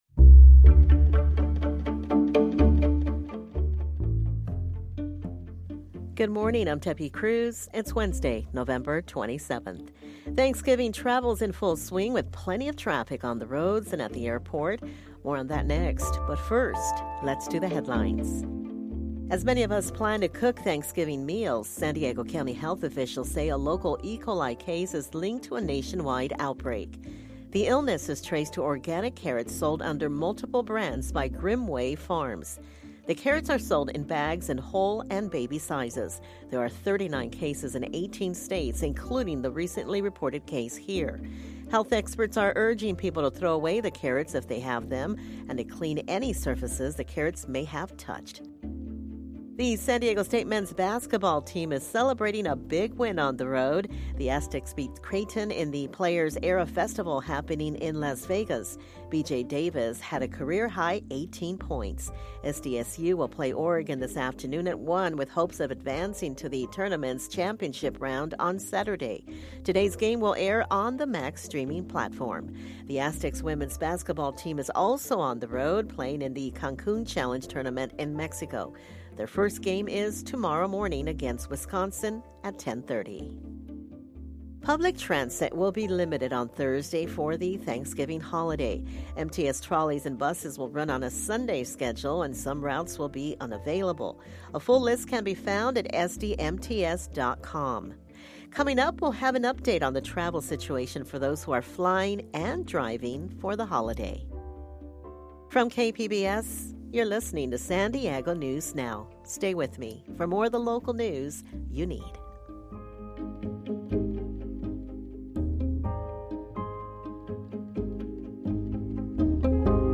San Diego news; when you want it, where you want it. Get local stories on politics, education, health, environment, the border and more.